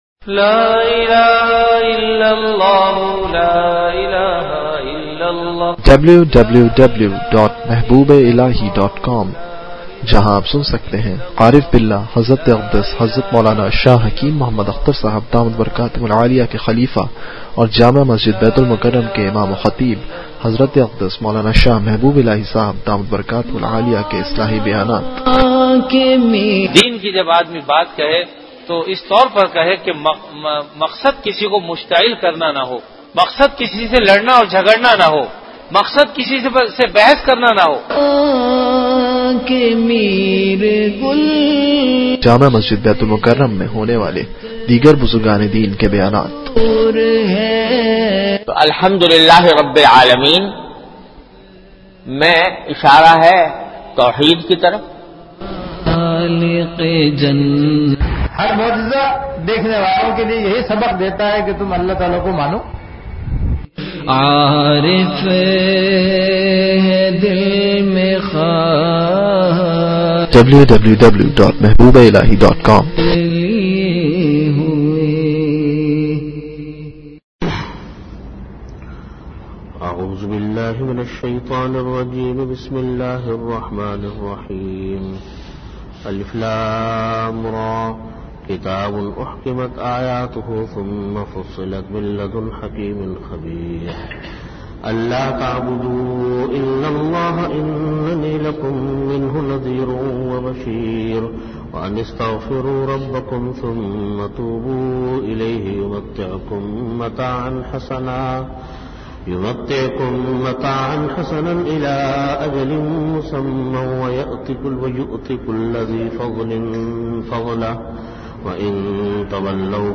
Audio Category: Bayanat Hits
62min Time: After Asar Prayer Venue: Jamia Masjid Bait-ul-Mukkaram, Karachi